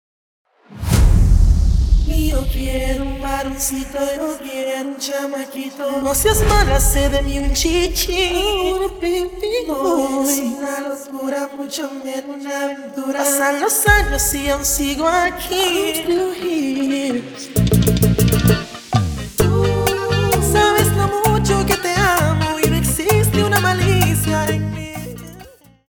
Extended Dirty Open Acapella